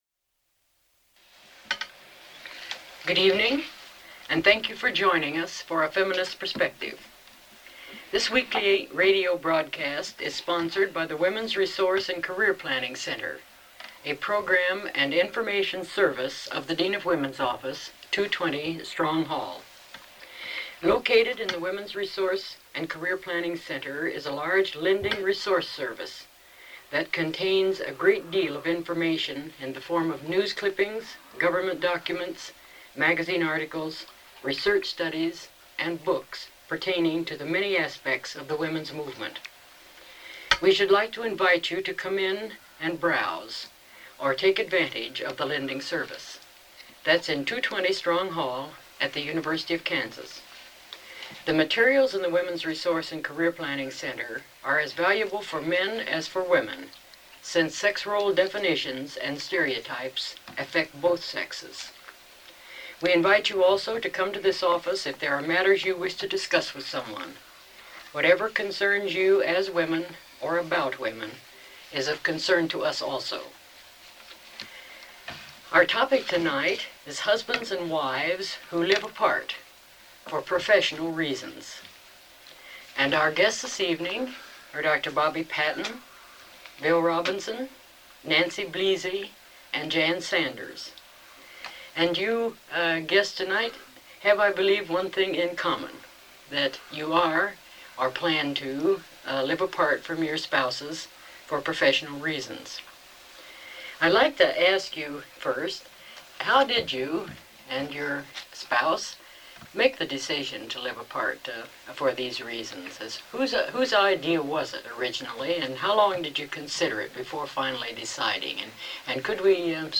Feminist Perspective radio program
Radio talk shows